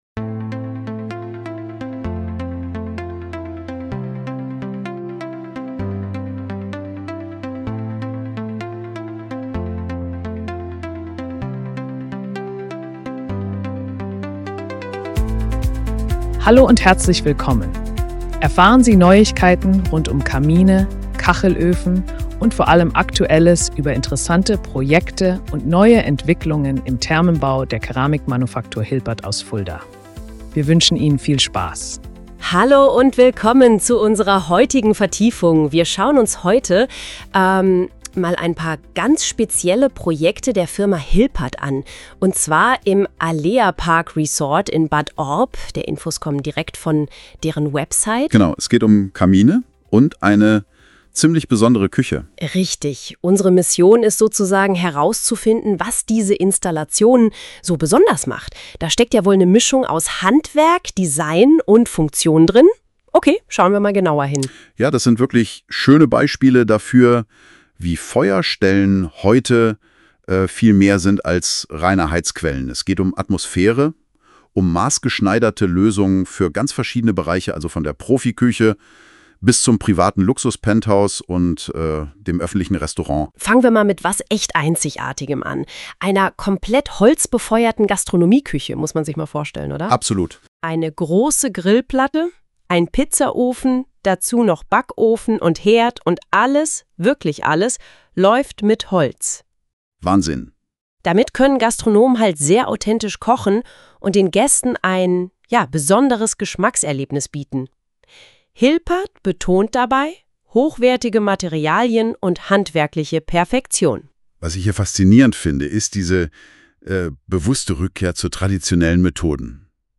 (Erstellt mit Verwendung verschiedener KI-Tools | Bookmark: Spotify)